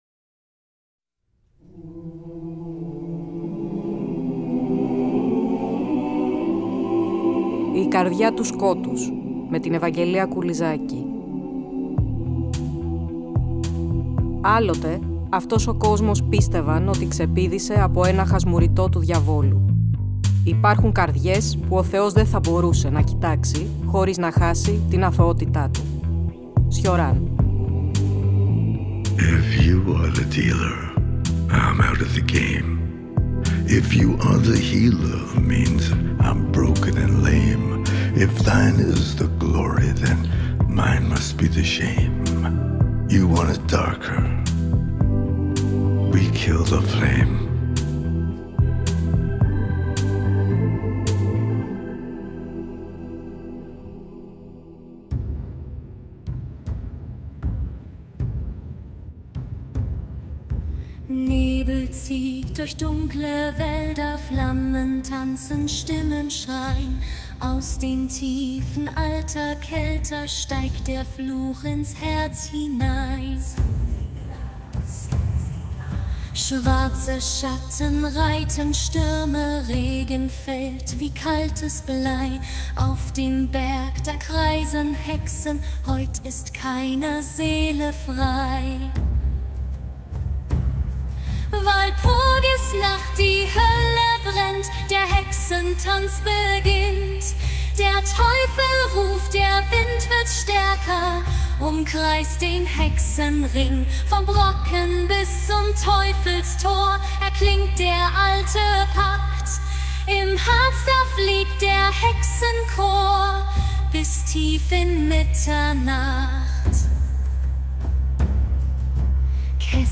Ακούστε το 14ο επεισόδιο του τρέχοντος Κύκλου της εκπομπής, που μεταδόθηκε την Κυριακή 07 Δεκεμβρίου από το Τρίτο Πρόγραμμα.